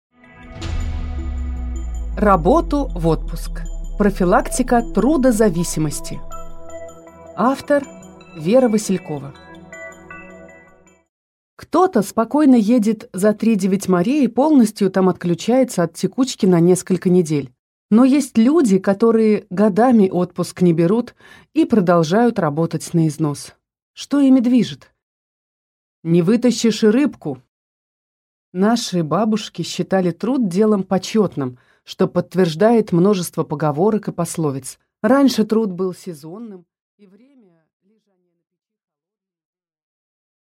Аудиокнига Работу – в отпуск!